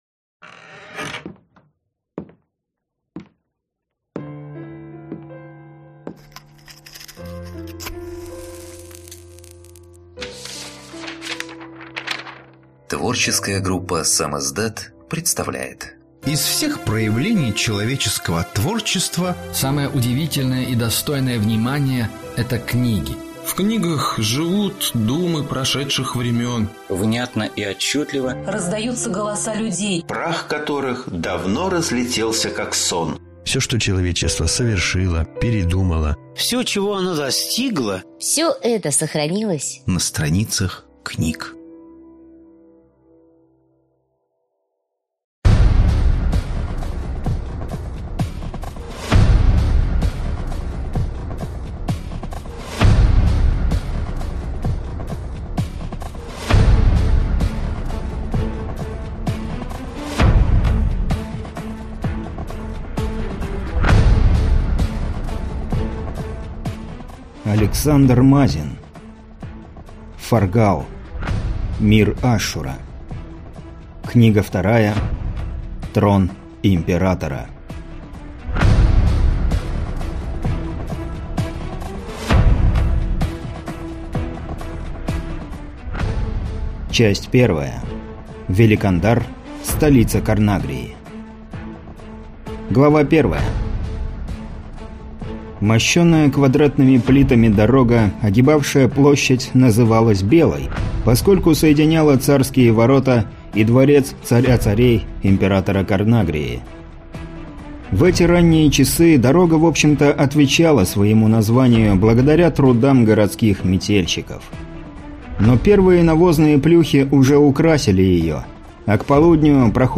Аудиокнига Трон императора | Библиотека аудиокниг